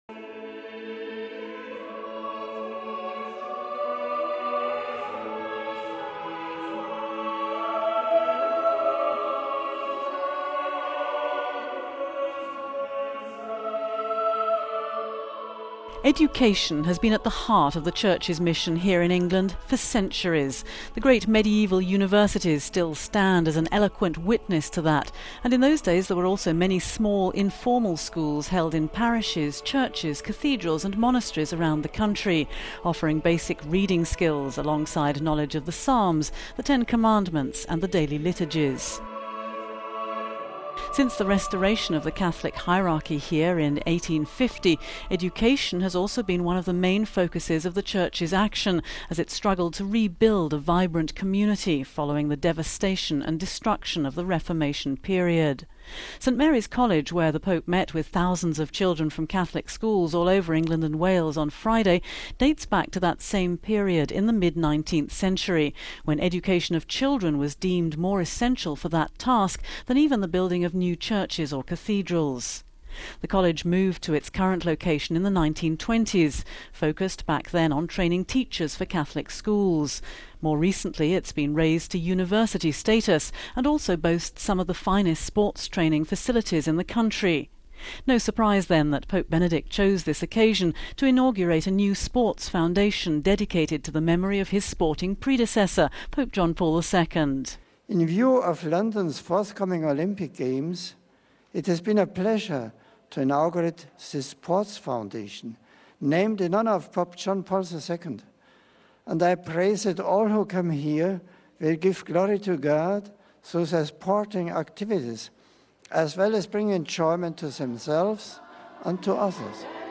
St Mary’s College, where the Pope met with thousands of children from Catholic schools all over England and Wales on Friday, dates back to that same period in the mid 19th century when education of children was deemed more essential for that task than even the building of new churches or cathedrals.
It was a relaxed and joyful encounter of the Pope with the excited kids and their teachers, gathered in their smart school uniforms alongside with a bunch of TV stars and Olympic athletes.